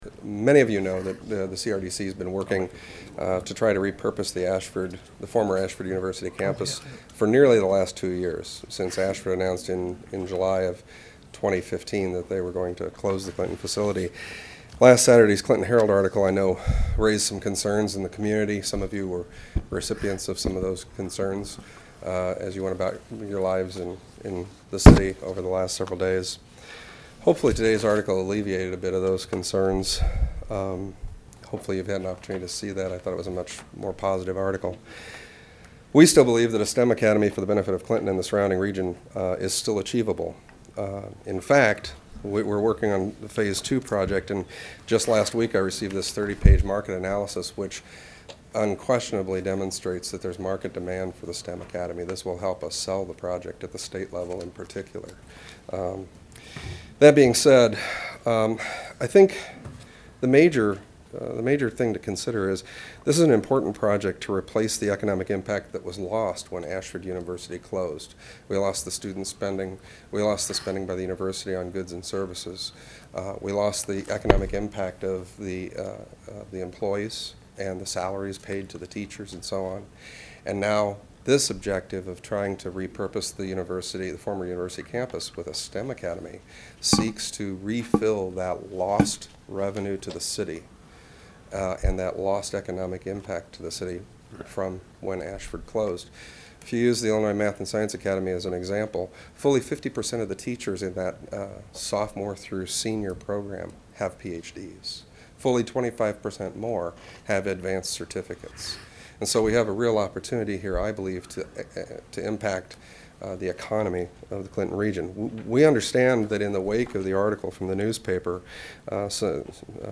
His comments were part of the audience comment section of the agenda.